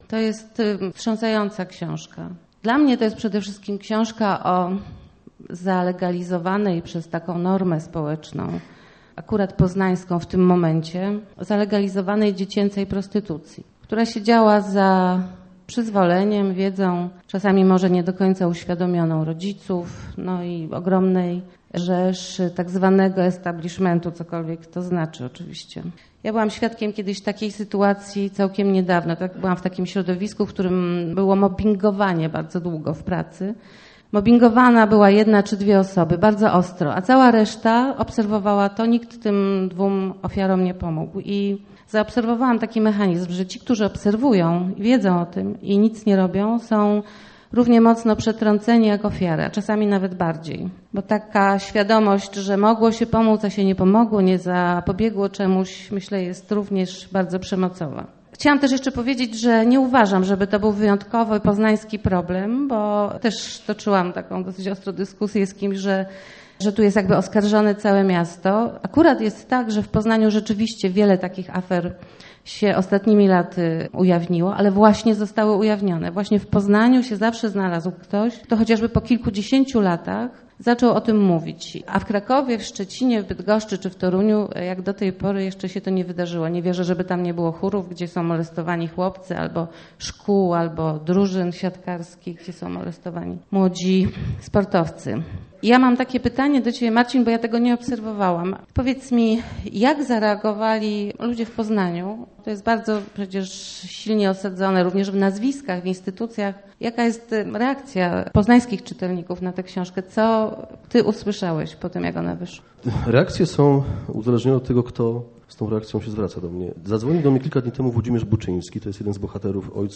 Maestro - relacja reporterska
W poznańskim Teatrze Ósmego Dnia, w minioną środę odbyła się publiczna debata o książce Marcina Kąckiego “Maestro”.